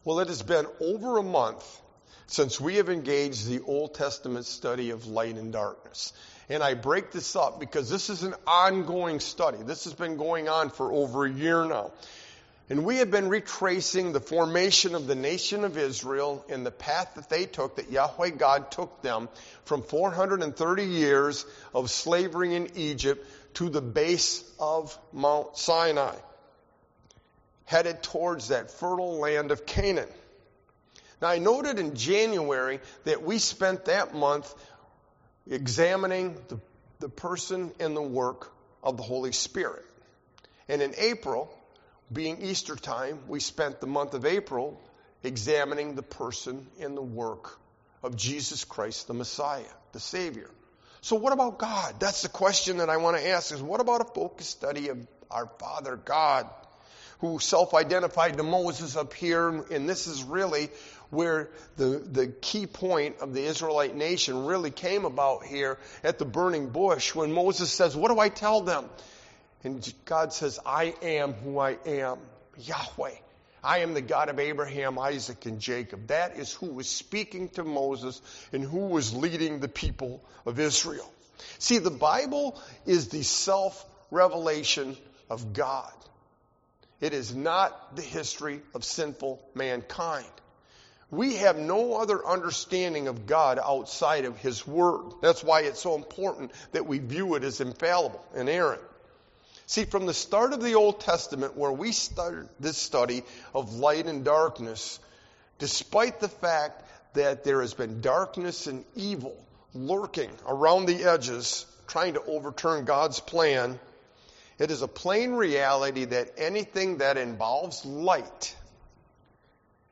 Sermon-Light-and-Darkness-LXI-5221.mp3